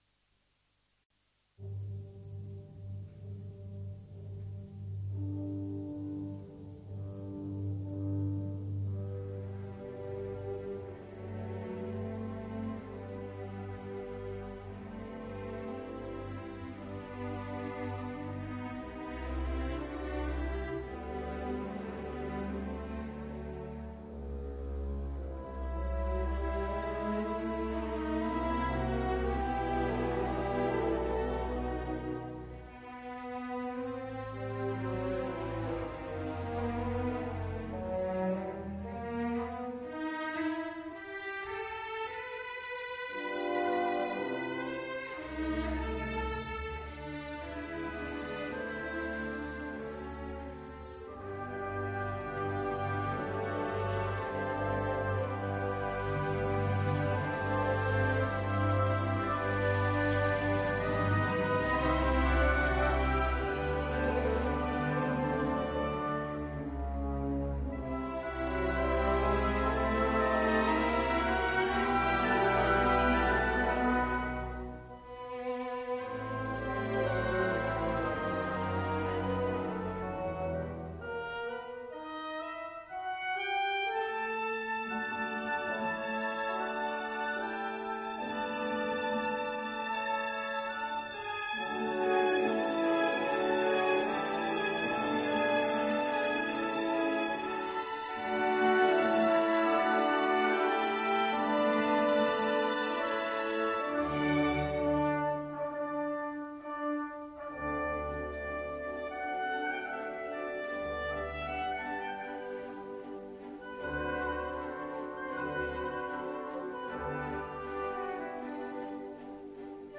registrazione in studio.
Coro